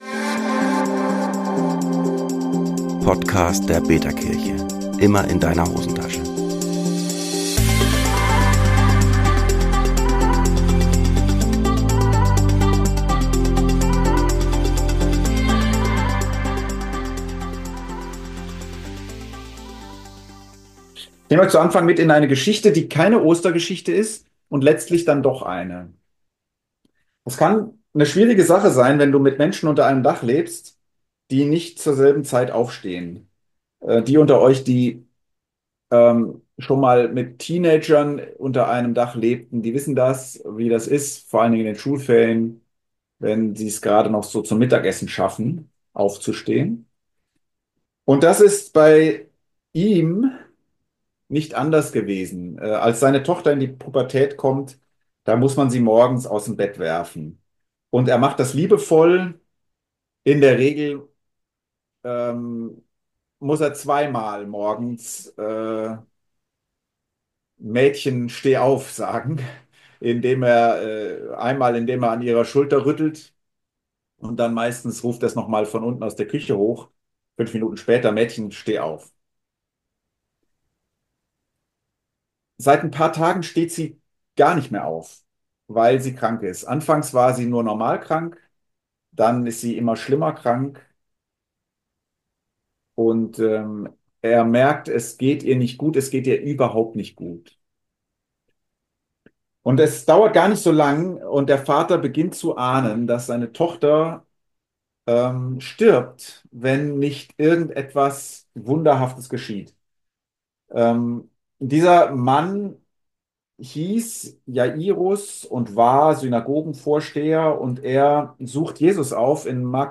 Predigt vom Ostergottesdienst der betaKirche am 20. April 2025.